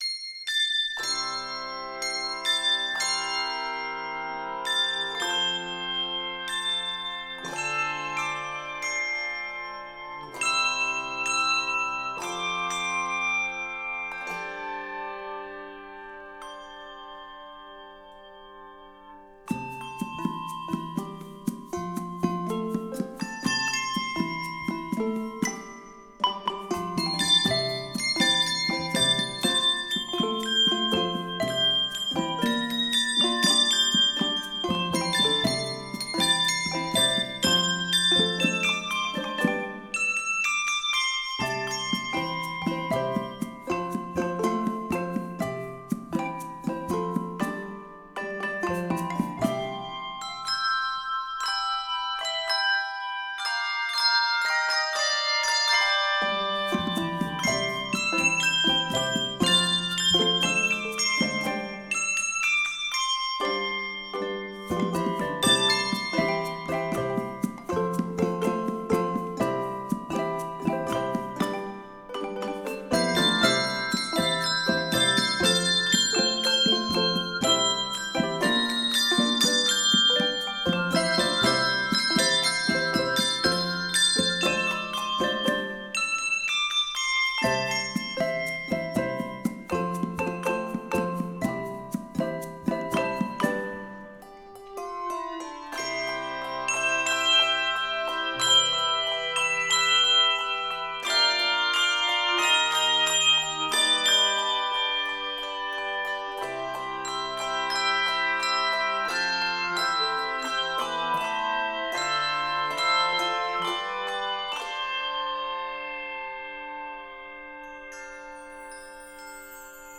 Voicing: Handbells 3-6 Octave